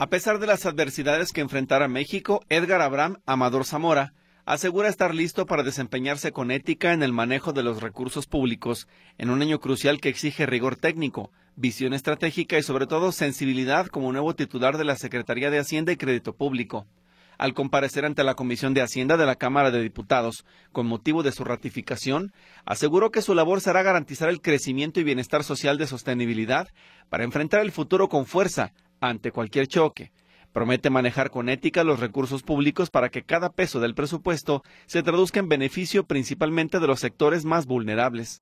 Al comparecer ante la Comisión de Hacienda de la Cámara de Diputados con motivo de su ratificación, aseguró que su labor será garantizar el crecimiento y bienestar social de sostenibilidad para enfrentar el futuro con fuerza ante cualquier choque.